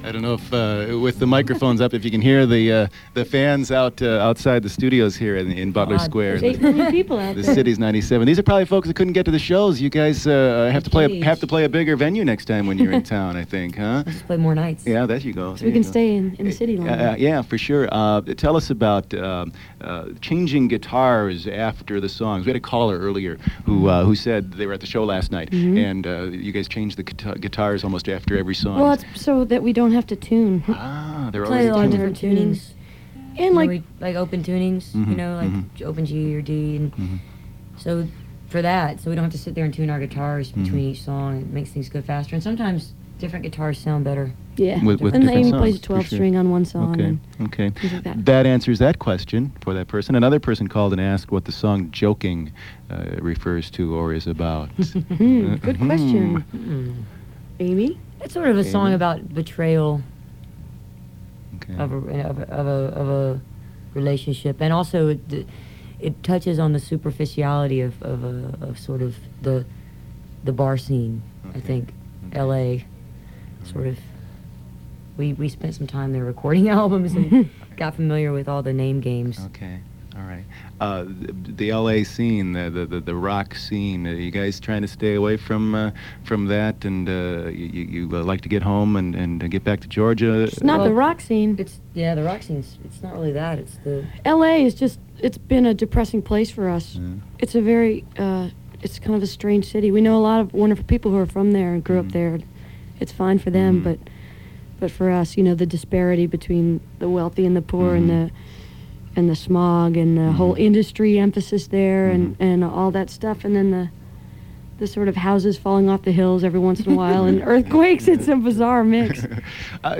05. interview (6:38)